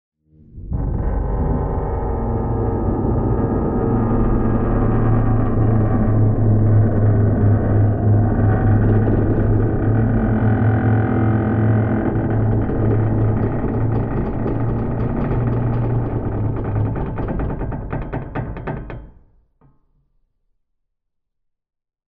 Звуки подводной лодки
Лодка трещит от давления на большой глубине — 03 мин 09 сек
Треск от давления воды — 22 сек